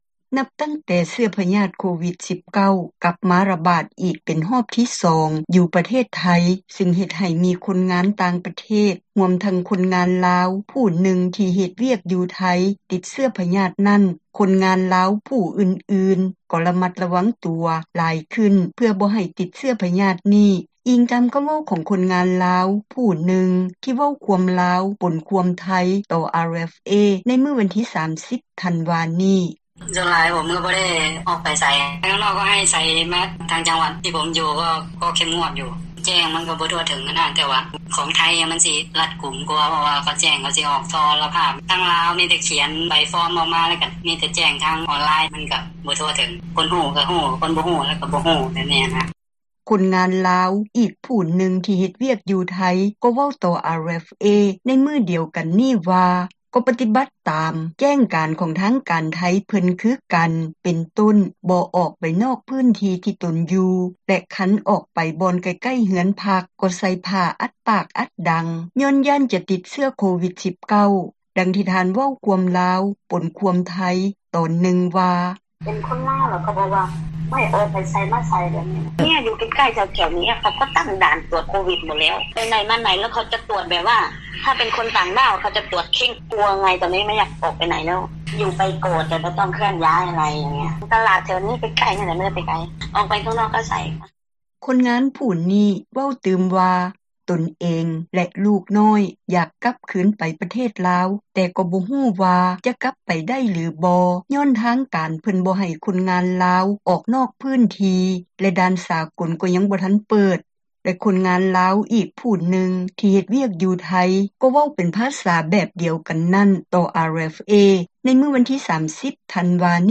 ຄົນງານລາວ ອີກຜູ້ນຶ່ງທີ່ເຮັດວຽກຢູ່ໄທ ກໍເວົ້າຕໍ່ RFA ໃນມື້ດຽວກັນນີ້ວ່າ ກໍປະຕິບັດຕາມ ແຈ້ງການຂອງທາງການໄທເພິ່ນຄືກັນ ເປັນຕົ້ນ ບໍ່ອອກໄປນອກພື້ນທີ່ທີ່ຕົນຢູ່ ແລະຄັນອອກໄປບ່ອນໃກ້ໆເຮືອນພັກ ກໍໃສ່ຜ້າອັດປາກອັດດັງ ຍ້ອນຢ້ານຈະຕິດເຊື້ອໂຄວິດ-19, ດັ່ງທີ່ທ່ານເວົ້າຄວາມລາວ ປົນຄວາມໄທ ຕອນນຶ່ງວ່າ: